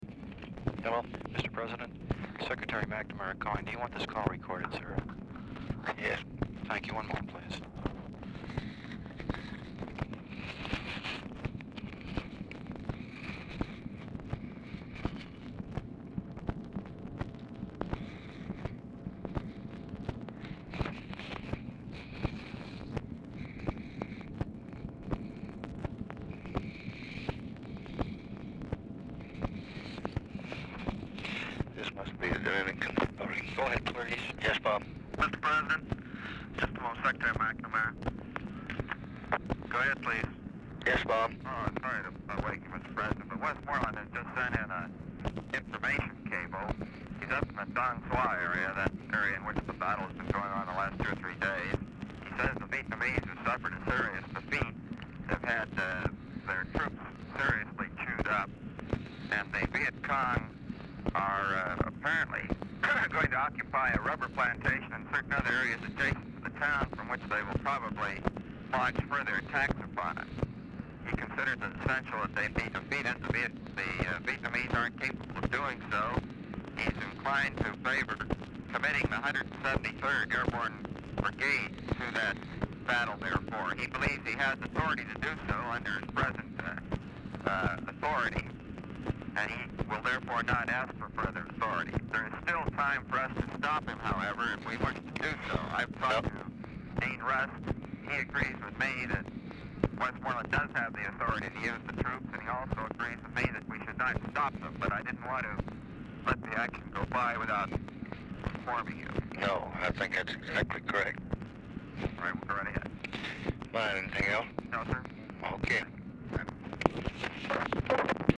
Telephone conversation # 8127, sound recording, LBJ and ROBERT MCNAMARA, 6/13/1965, 2:45AM
SIGNAL CORPS OPERATOR ASKS LBJ IF HE WANTS CALL RECORDED
Format Dictation belt